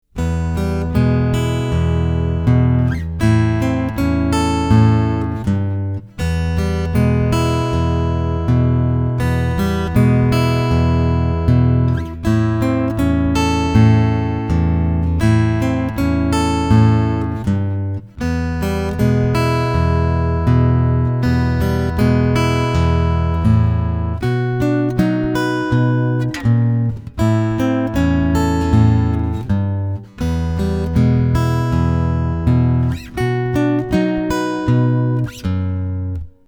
Easy Acoustic Blues Pattern – Study
To finish up with this easy acoustic blues pattern, here is a full chorus of a Blues in E that uses the pattern for each bar in the chord progression, over the E7, A7 and B7 chords respectively.
easy-blues-full-track.mp3